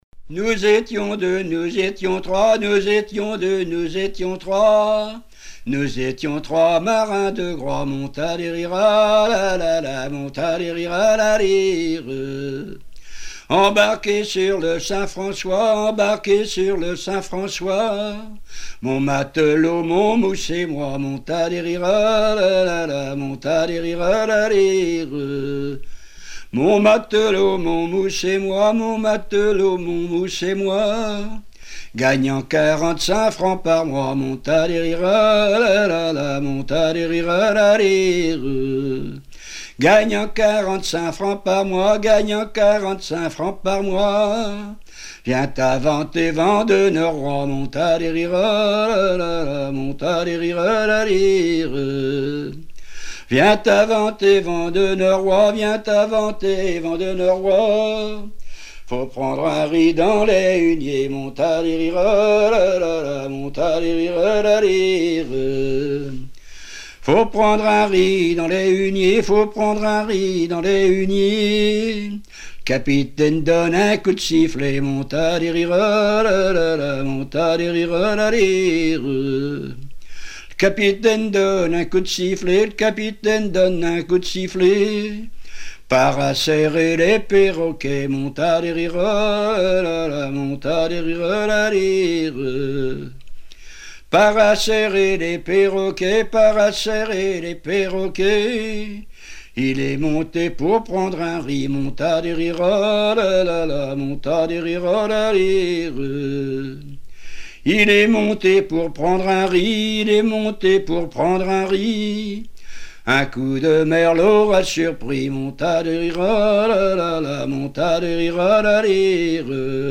circonstance : maritimes
Genre laisse